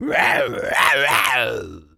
tas_devil_cartoon_11.wav